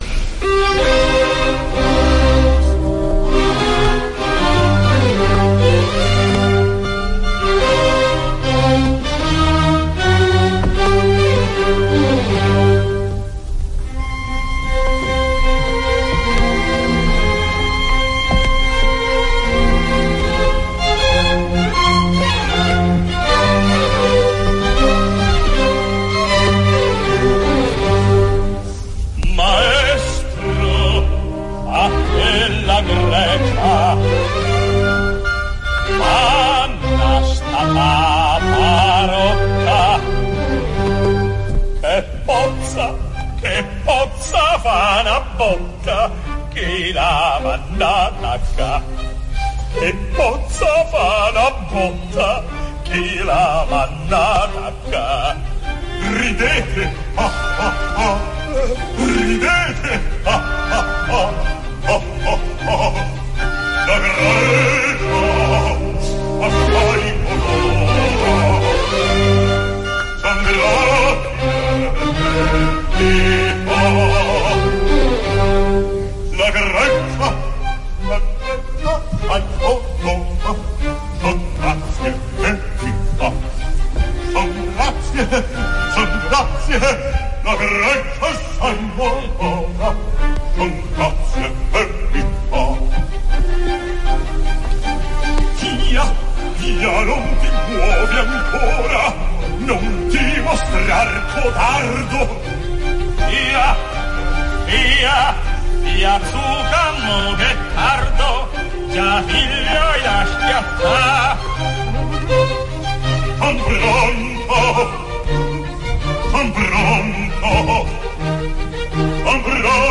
opera completa, registrazione dal vivo.